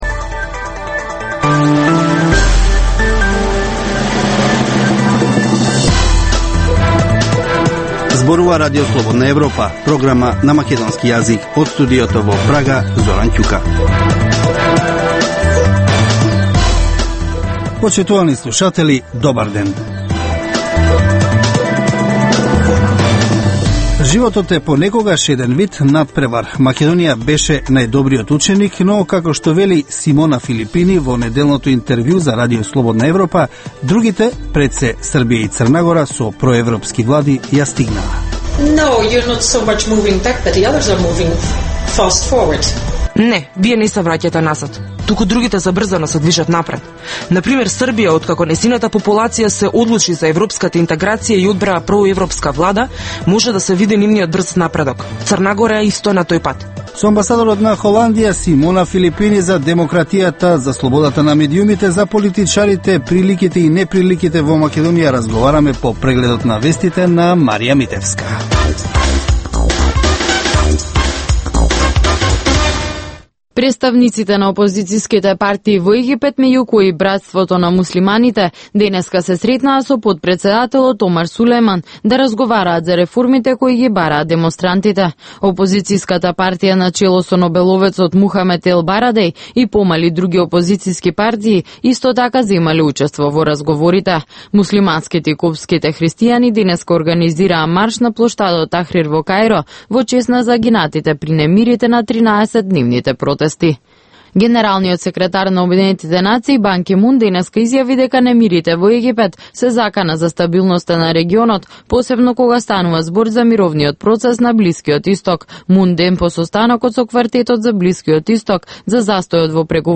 Информативна емисија, секој ден од студиото во Прага. Вести, актуелности и анализи за случувања во Македонија на Балканот и во светот.